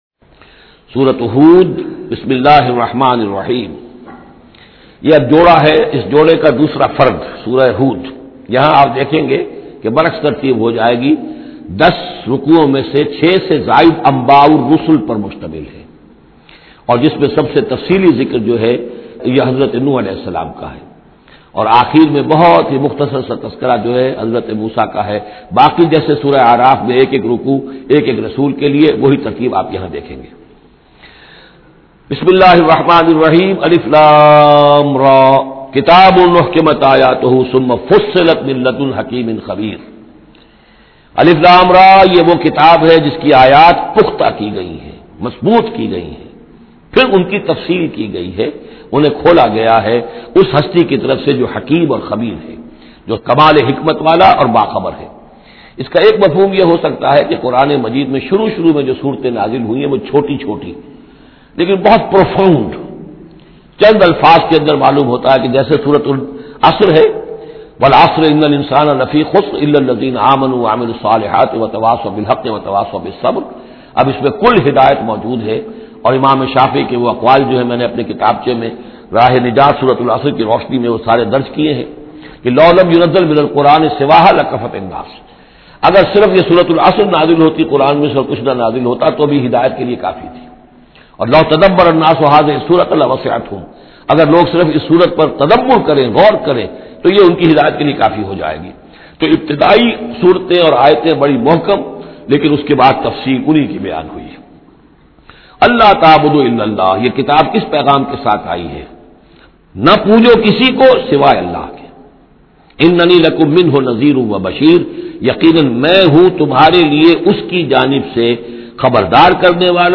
Surah Hud Tafseer by Dr Israr Ahmed
Listen in the voice of Dr Israr Ahmed.